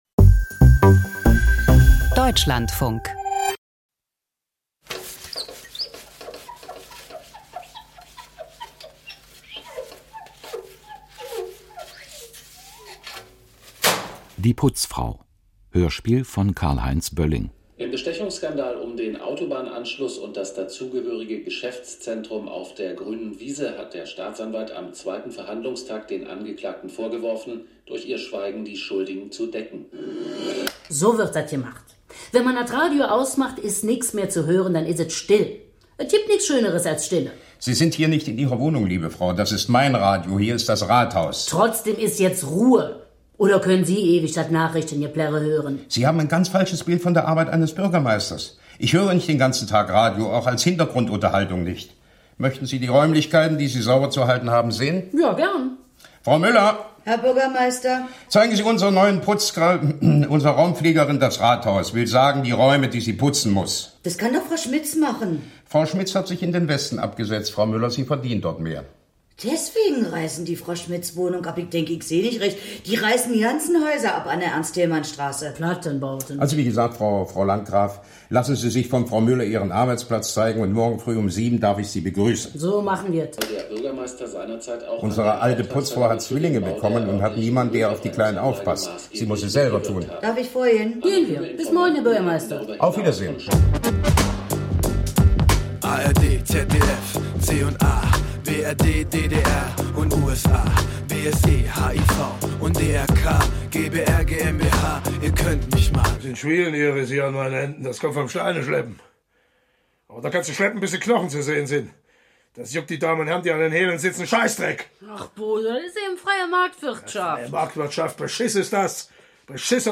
Kriminalhörspiel